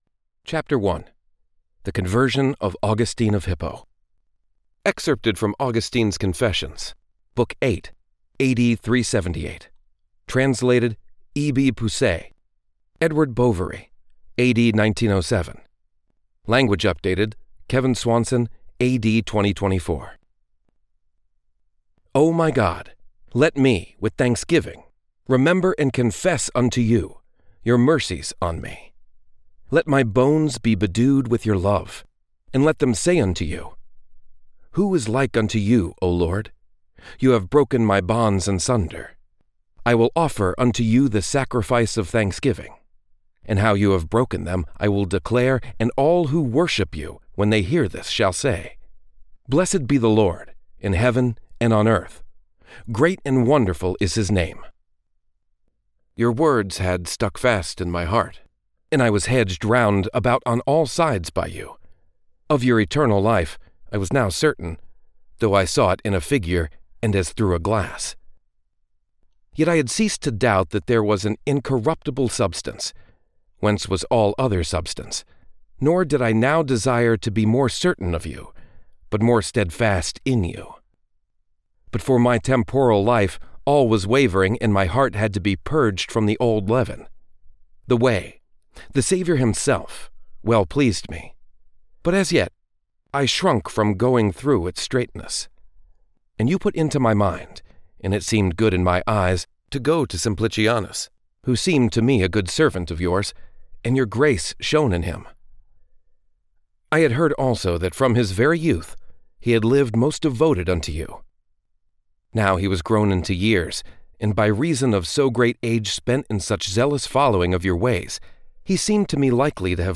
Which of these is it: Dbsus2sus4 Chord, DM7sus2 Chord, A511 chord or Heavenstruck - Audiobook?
Heavenstruck - Audiobook